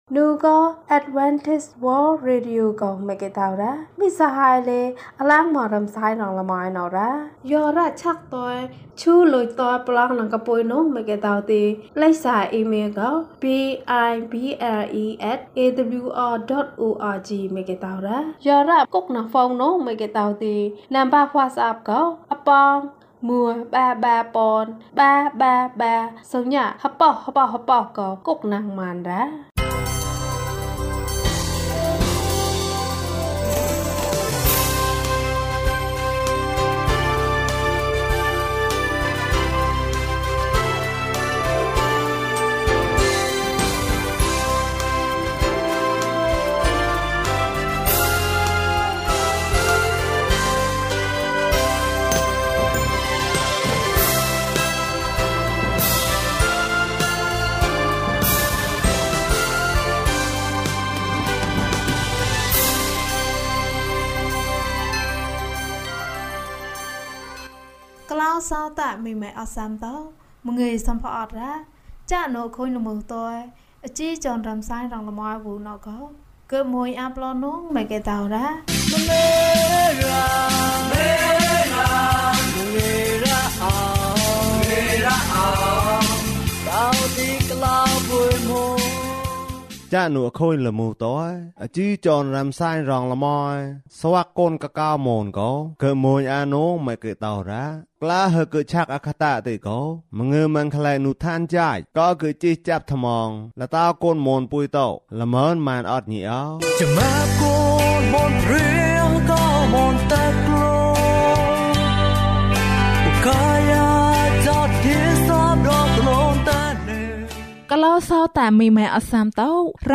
လူတွေက ယေရှုကို လိုအပ်တယ်။၀၁ ကျန်းမာခြင်းအကြောင်းအရာ။ ဓမ္မသီချင်း။ တရားဒေသနာ။